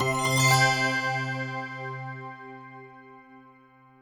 I suoni sono stati ricavati tutti da installazioni di software originale proveniente dalla mia collezione privata di programmi antichi, installazioni eseguite su macchine virtuali "VirtualBox".
Apertura desktop
suono molto forte e deciso
linuxmint-login.wav